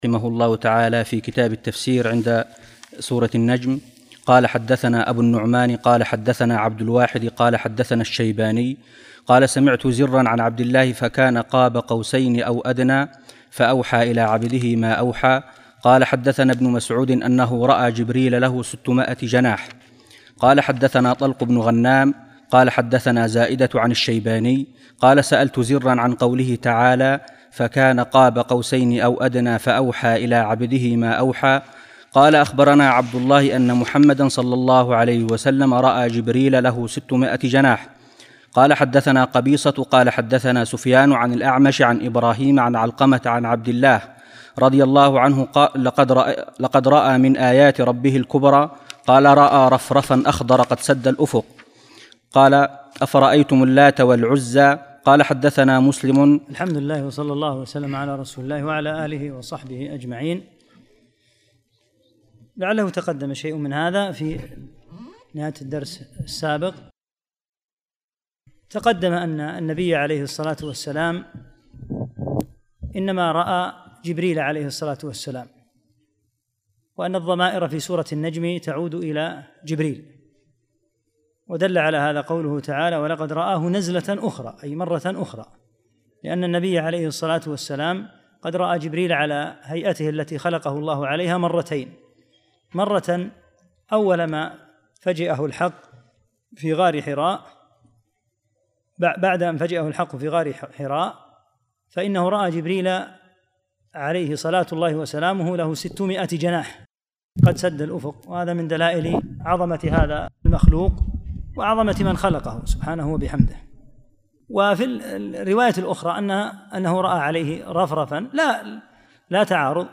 45- الدرس الخامس والأربعون